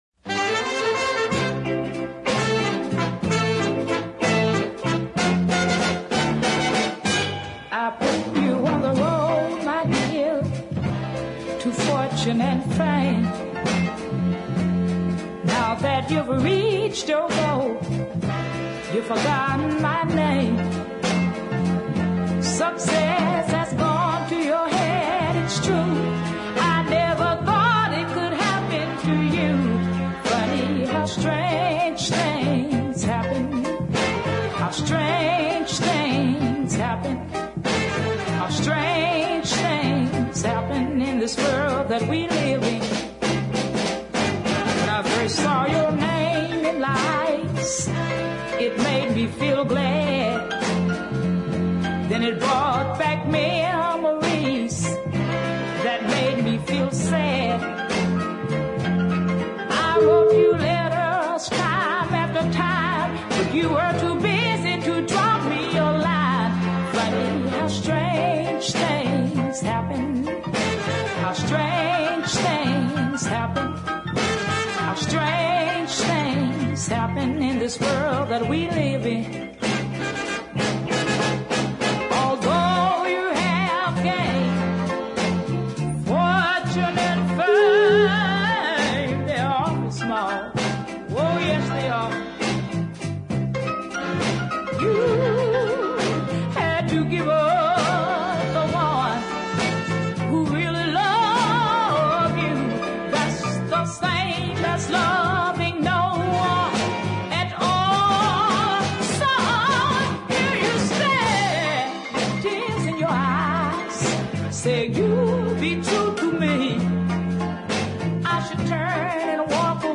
Detroit singer
emotive tones and excellent sense of timing